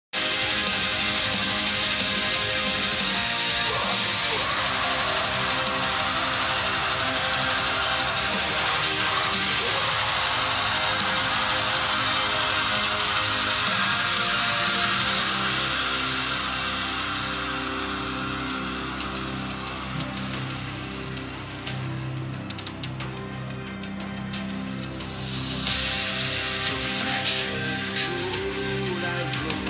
metal song